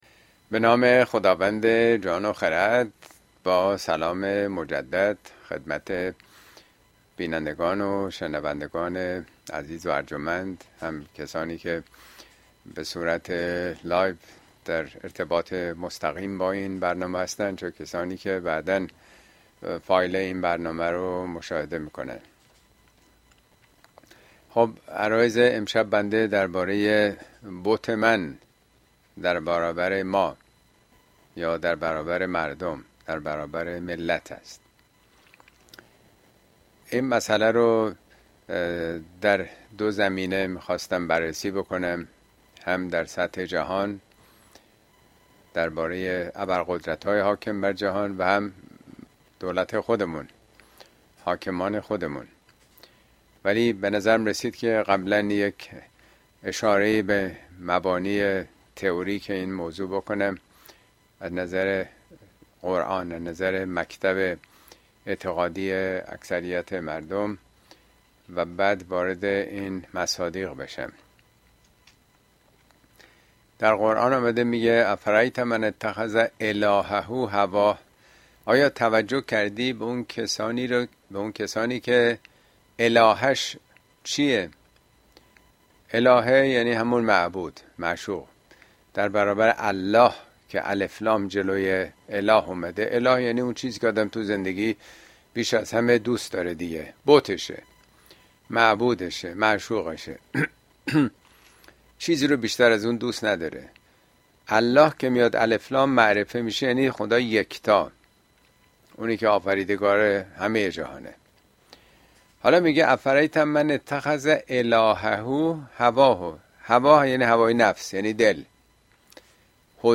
Speech
` موضوعات اجتماعى اسلامى !بُت �من� در برابر مردم اين سخنرانى به تاريخ ۲۹ می ۲۰۲۴ در كلاس آنلاين پخش شده است توصيه ميشود براىاستماع سخنرانى از گزينه STREAM استفاده كنيد.